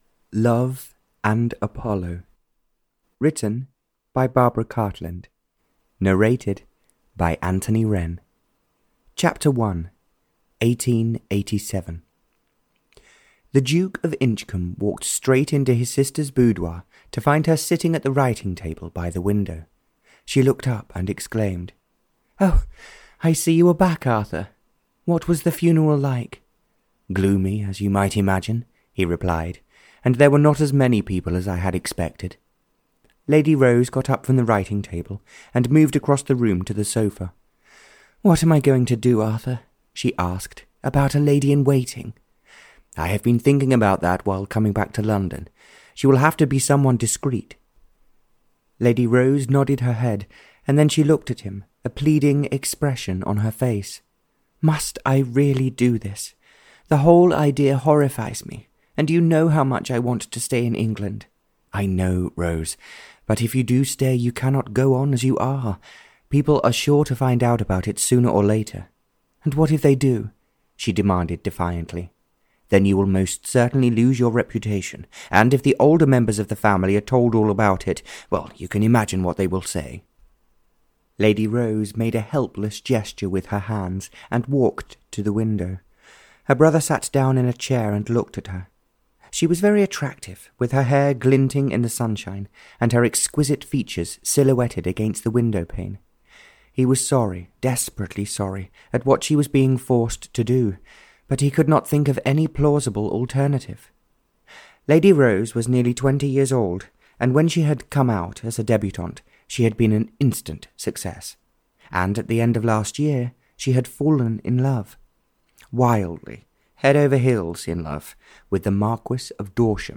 Love and Apollo (EN) audiokniha
Ukázka z knihy